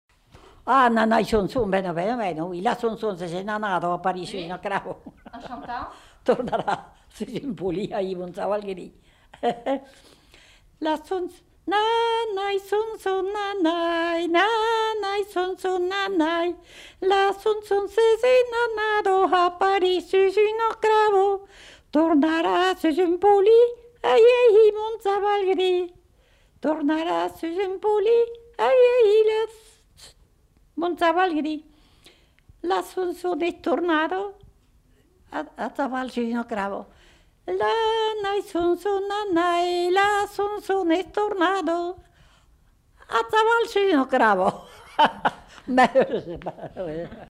Aire culturelle : Haut-Agenais
Lieu : Condezaygues
Genre : chant
Type de voix : voix de femme
Production du son : chanté
Classification : som-soms, nénies